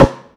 Snare 03.wav